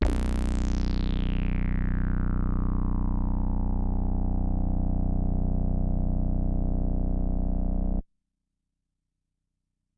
Deckard's Dream（DDRM）是一款由Black Corporation设计的8声道模拟合成器，灵感来自经典雅马哈CS80。
标签： 类似物 单票据 DDRM 多重采样 B0 合成器 deckardsdream 合成仪 CS80 MIDI网速度16 迷你笔记-23
声道立体声